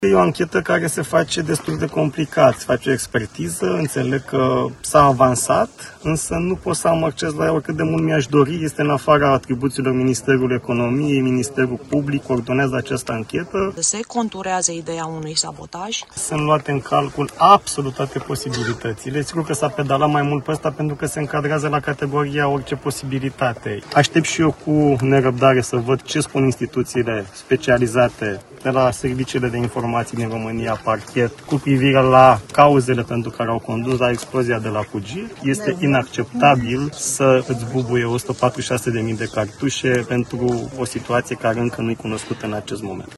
Ministrul Economiei, Radu Miruță, spune că este o anchetă complicată și nu exclude ipoteza unui sabotaj.